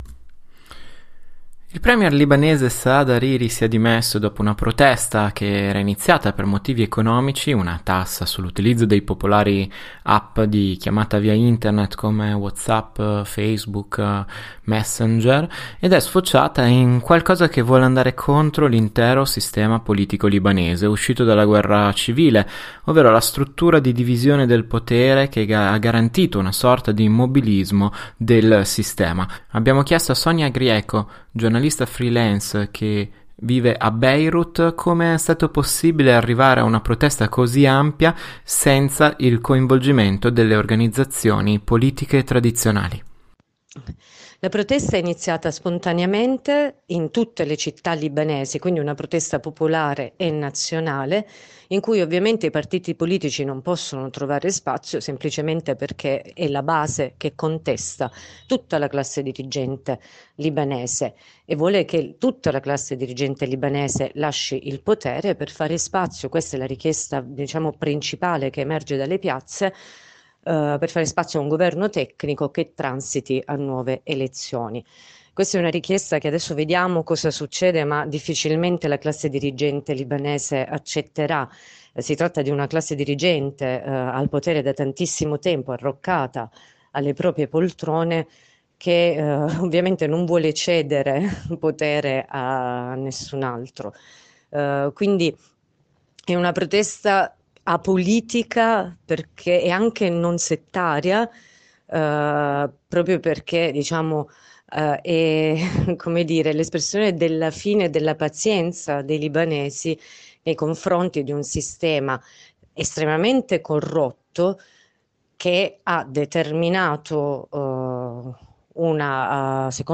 giornalista freelance